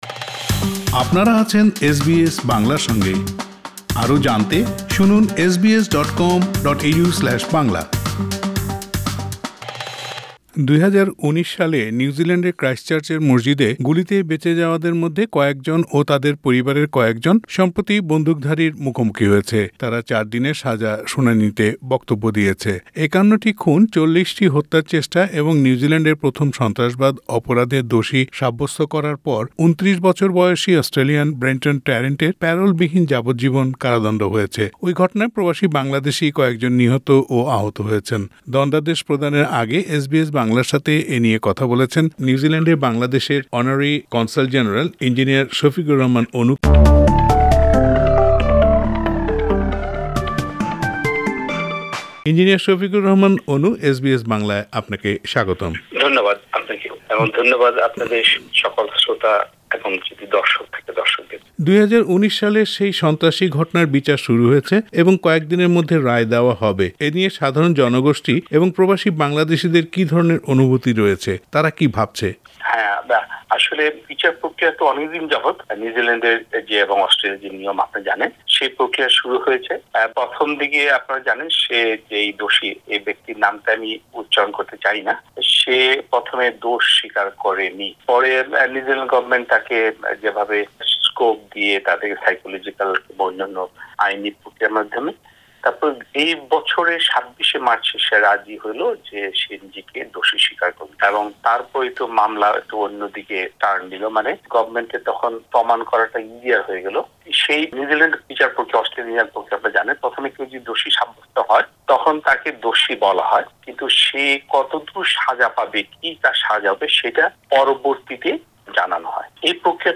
Several expatriate Bangladeshis were killed and injured in the incident. Engineer Shafiqur Rahman Anu, Honorary Consul General of Bangladesh in New Zealand spoke to SBS Bangla. Listen to the interview in Bangla in the audio-player above.